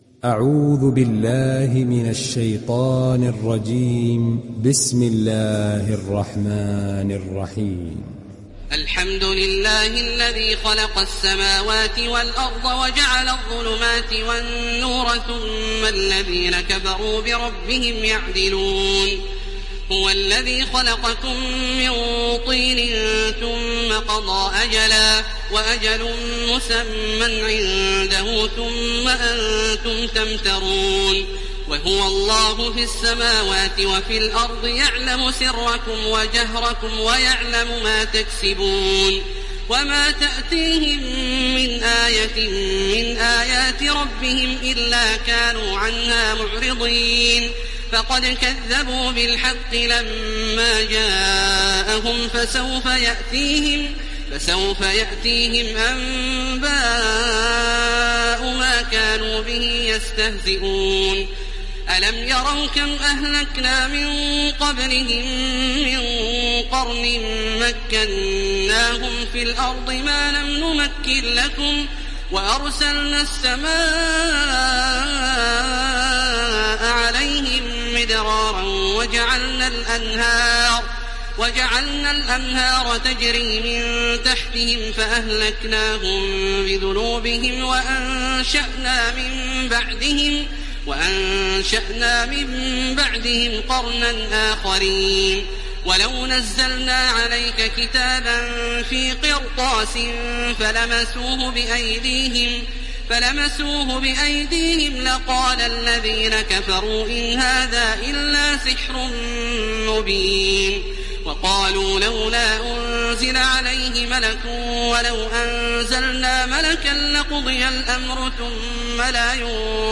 دانلود سوره الأنعام تراويح الحرم المكي 1430